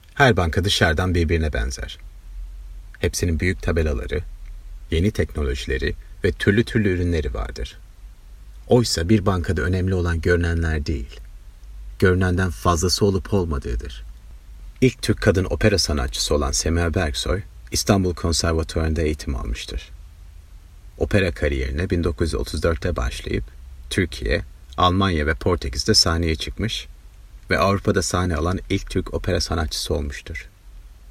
Middle Eastern, Turkish, Male, 30s-40s